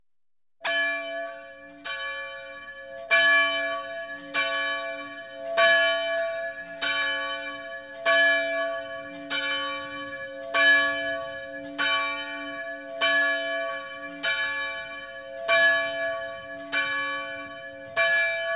Temple Bell Sound Effect Free Download
Temple Bell